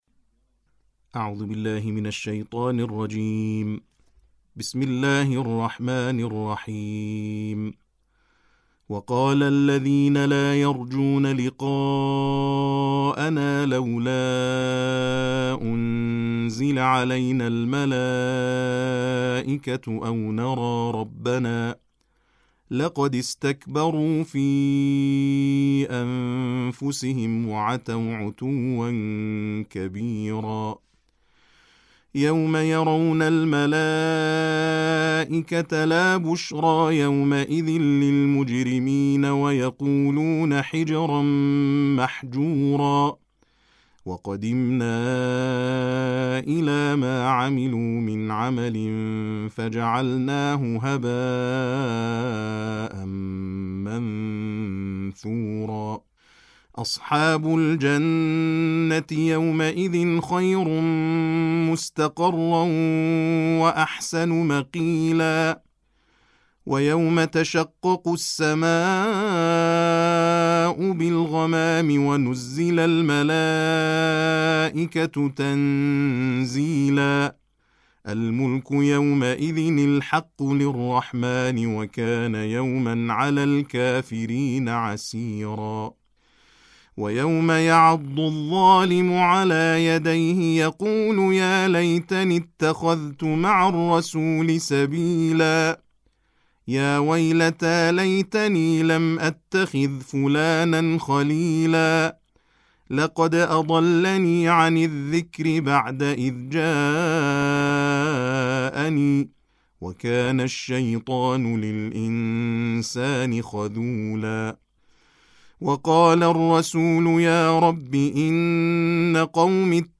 تلاوت ترتیل جزء نوزدهم قرآن